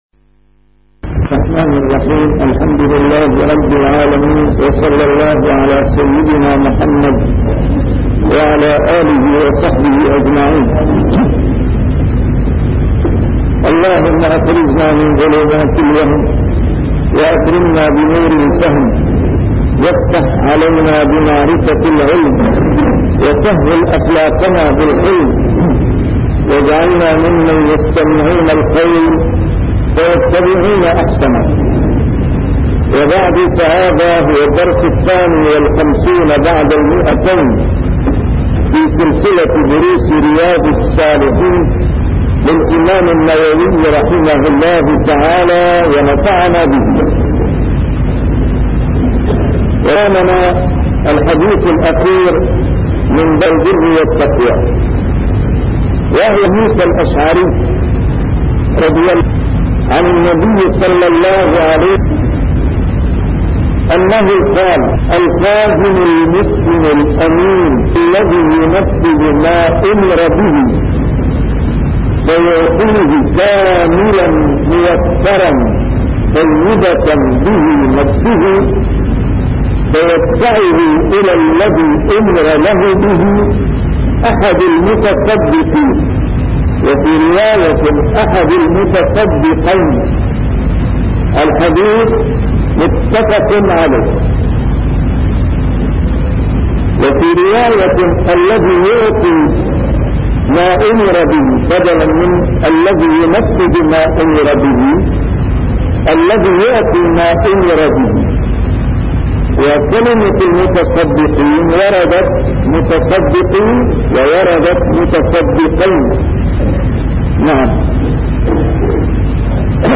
A MARTYR SCHOLAR: IMAM MUHAMMAD SAEED RAMADAN AL-BOUTI - الدروس العلمية - شرح كتاب رياض الصالحين - 252- شرح رياض الصالحين: التعاون على البر والتقوى